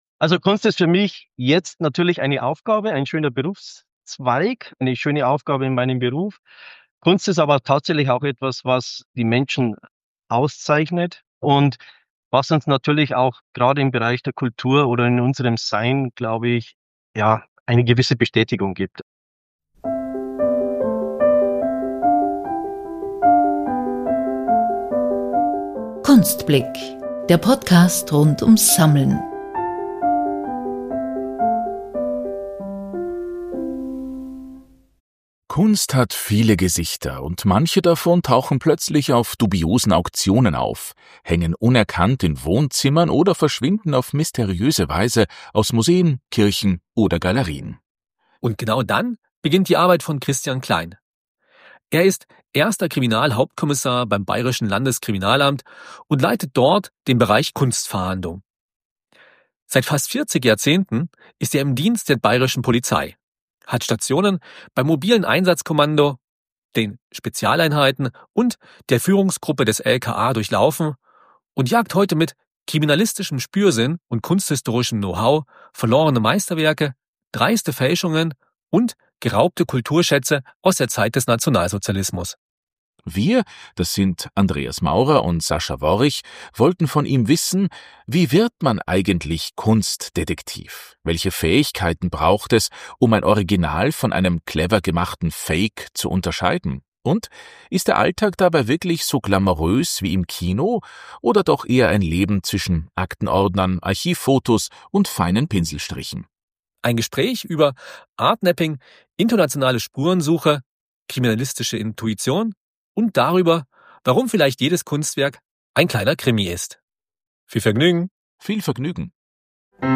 Ein Gespräch über „Art-Napping“, internationale Spurensuche, kriminalistische Intuition – und dar über, warum vielleicht jedes Kunstwerk ein kleiner Krimi ist.